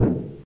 mech_walk_2.ogg